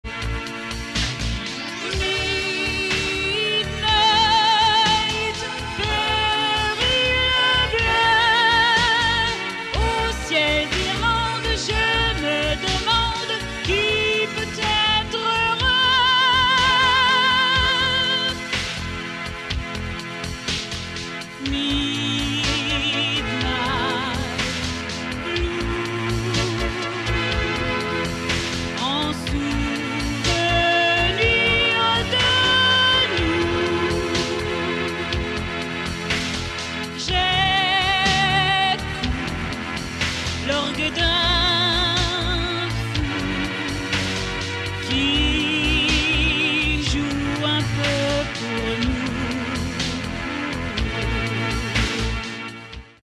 EXTRAIT SLOWS